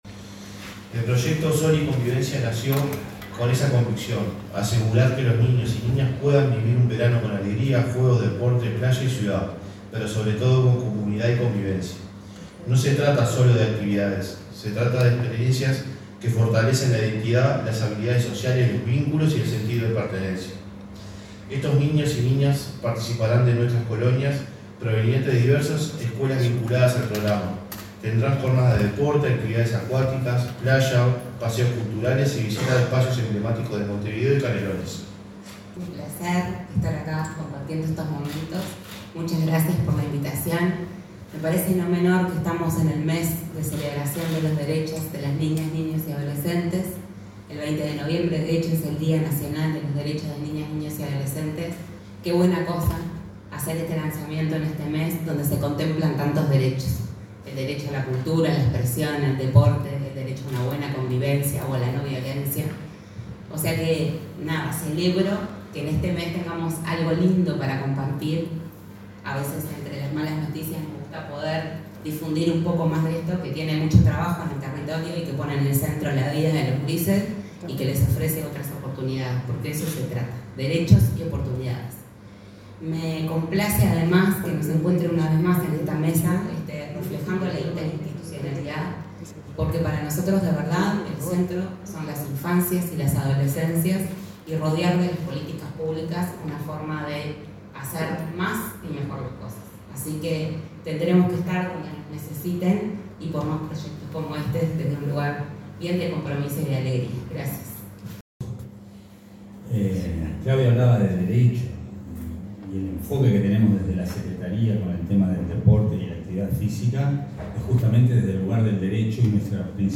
Palabras de autoridades de Gobierno en presentación programa del Ministerio del Interior
La presidenta del Instituto del Niño y del Adolescente del Uruguay (INAU), Claudia Romero, el secretario nacional del Deporte, Alejandro Pereda, y el director del programa Pelota al Medio a la Esperanza, Agustín Iparraguirre, hicieron uso de la palabra en la presentación de programa de escuelas de verano Sol y Convivencia, de Pelota al Medio a la Esperanza.